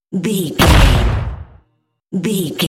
Dramatic hit door slam
Sound Effects
heavy
intense
dark
aggressive